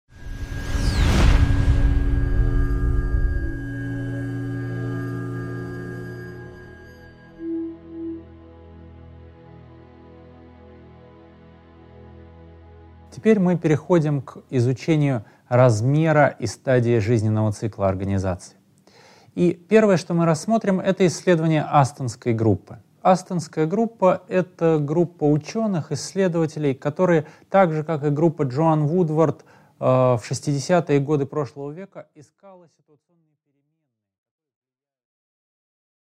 Аудиокнига 5.5. Размер: исследование Астонской группы | Библиотека аудиокниг